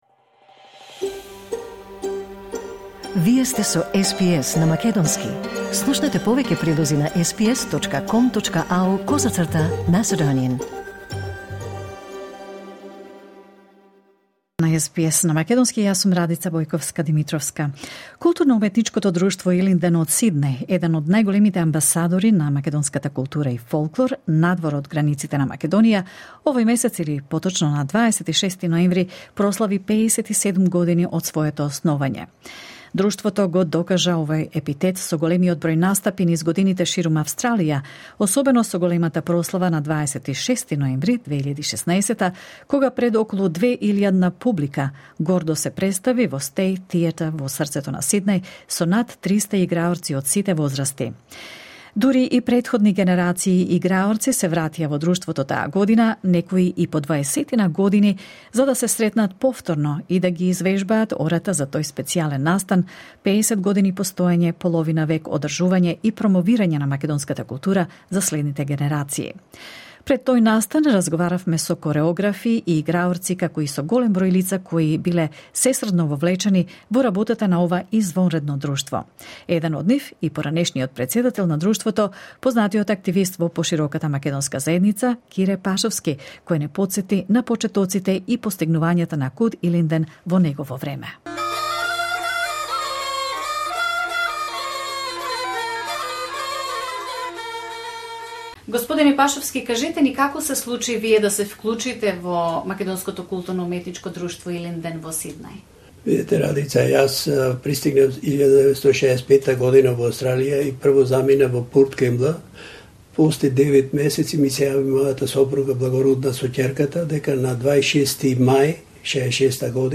архивски разговор